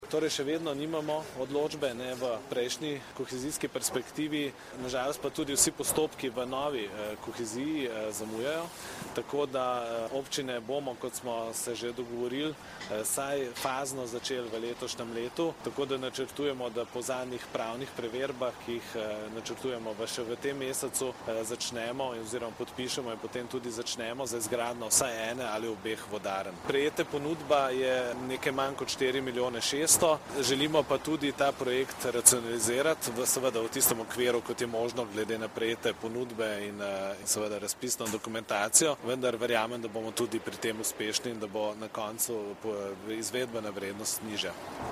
Novo mesto, 8. 5. 2015 – Danes dopoldne je župan Mestne občine Novo mesto Gregor Macedoni novinarje seznanil z novostmi pri aktualnih projektih Mestne občine Novo mesto.
Župan Gregor Macedoni o projektu Hidravlične izboljšave vodovodnega sistema osrednje Dolenjske